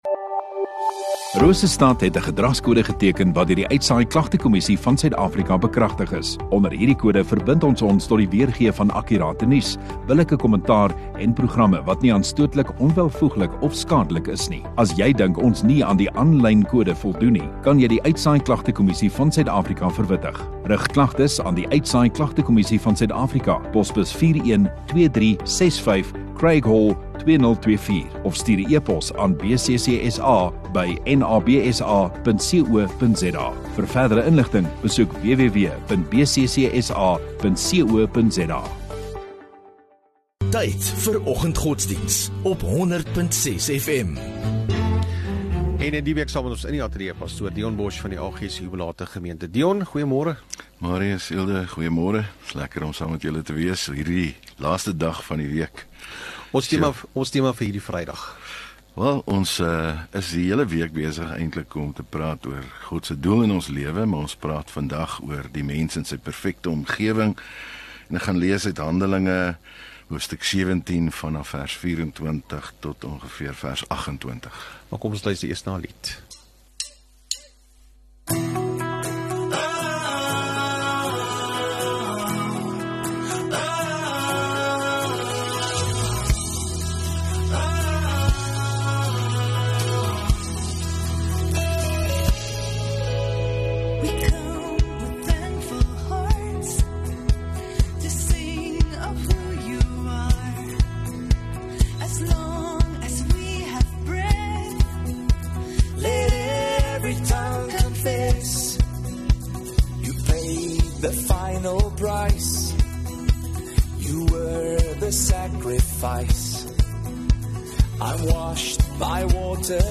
25 Oct Vrydag Oggenddiens